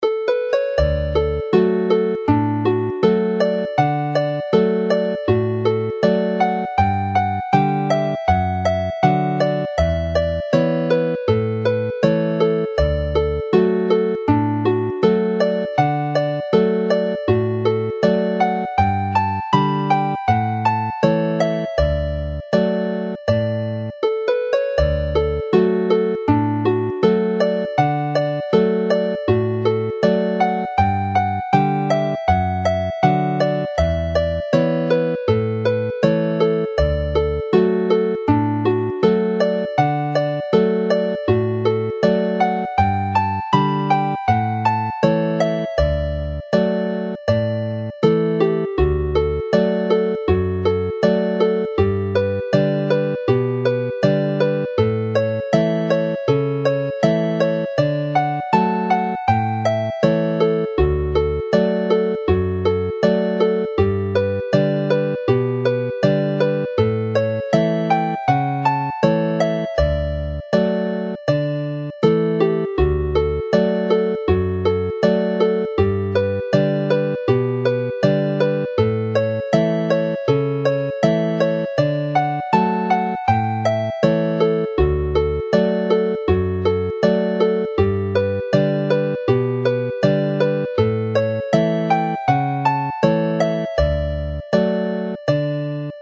as a reel
Play the tune slowly